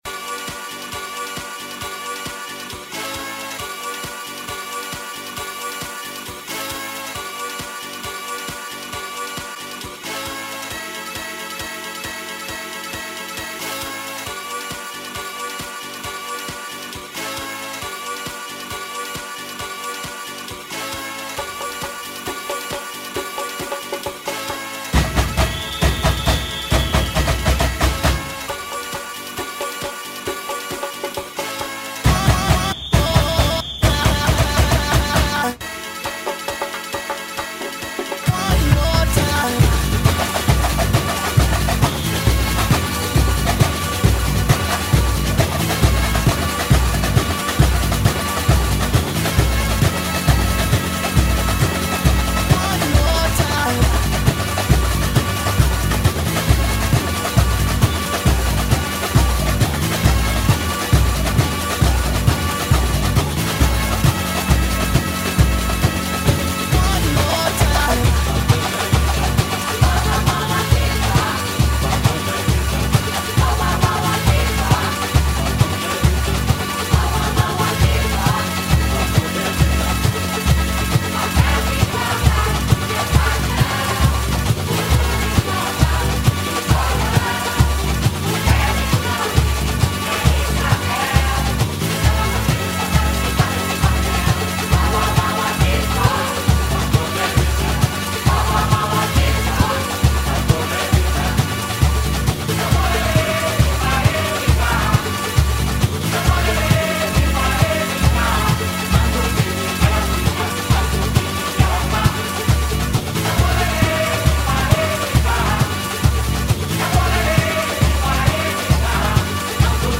Unknown Batucada